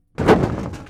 WoodItemDropping.ogg